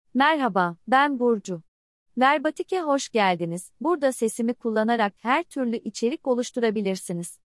Burcu — Female Turkish AI voice
Burcu is a female AI voice for Turkish.
Voice sample
Female
Burcu delivers clear pronunciation with authentic Turkish intonation, making your content sound professionally produced.